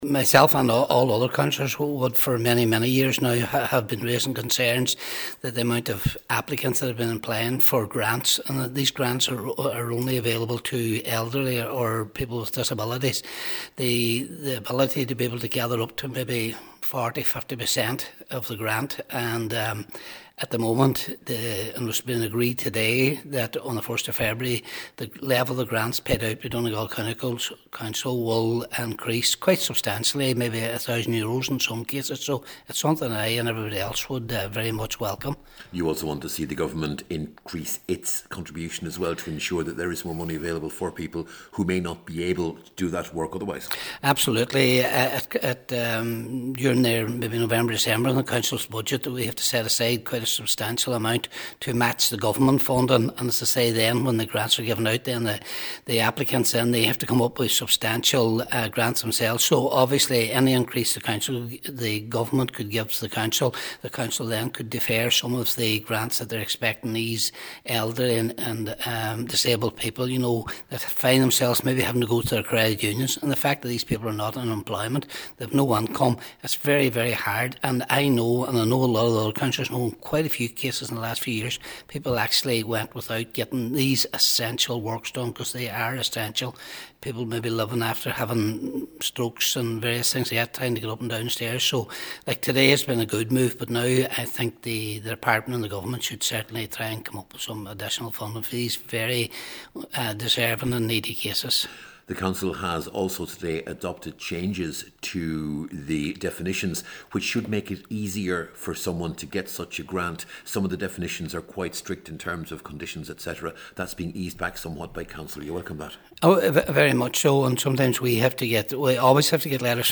Cllr Patrick Mc Gowan says it’s an important step forward: